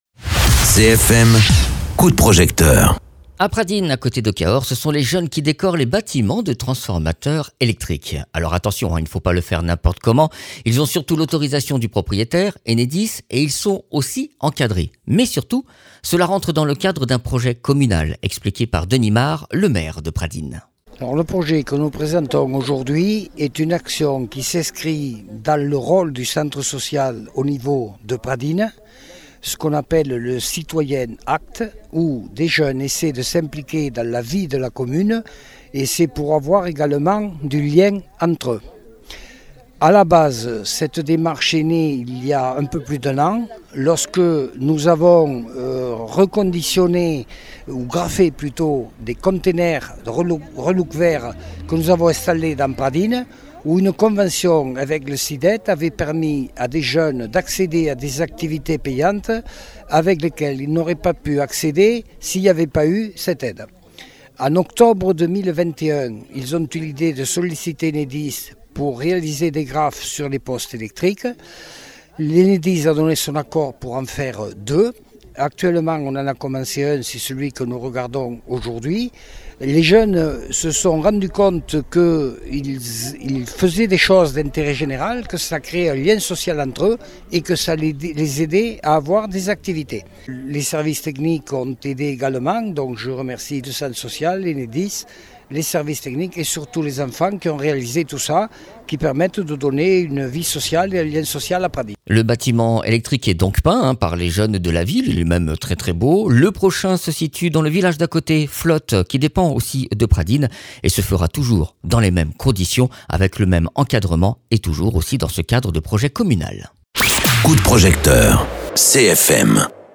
Interviews
Invité(s) : Denis Marre, Maire de Pradines